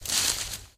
sounds / material / human / step / bush01gr.ogg